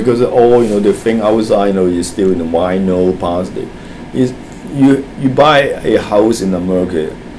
S1 = Taiwanese female S2 = Hong Kong male Context: S2 has been talking about how buildings are built in America, and why it is necessary to keep working to maintain your property..
The problem with plastic is (as before; see HK2 : 1564 ) the absence of [l] and final [k] .